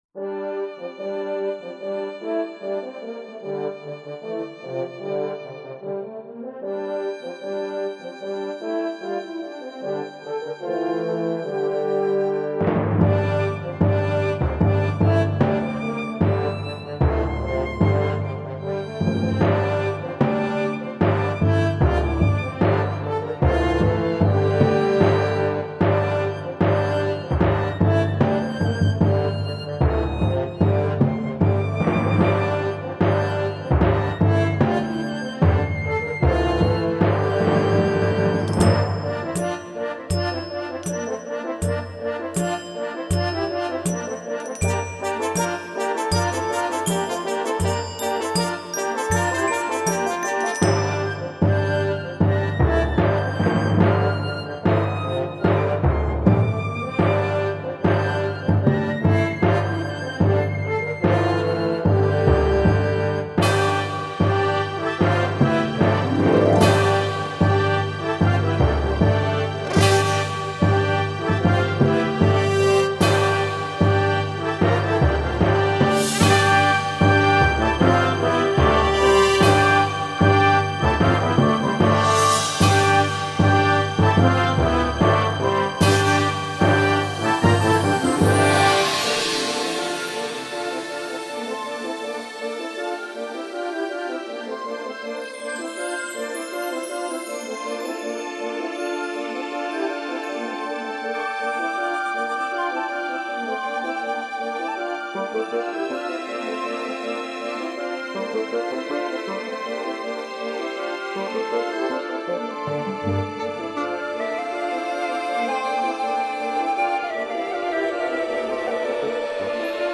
موسیقی بی کلام 3